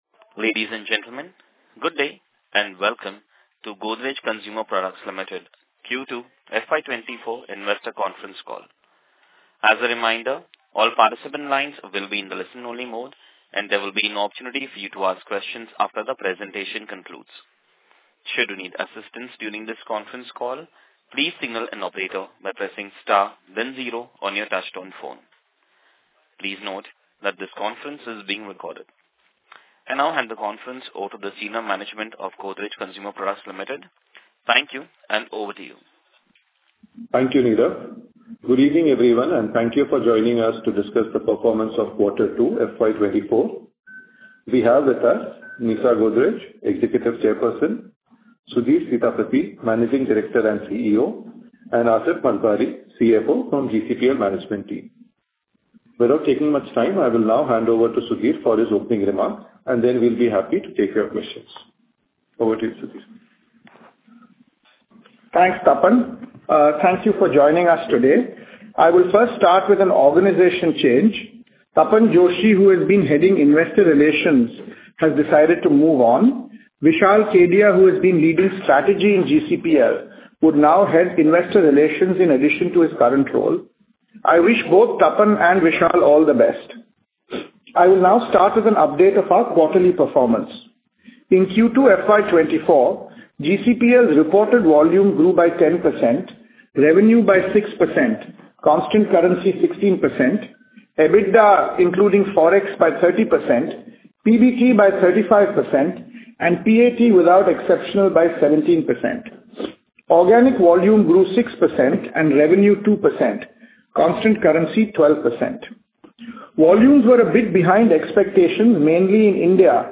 Playback of Q3FY26 Earnings Call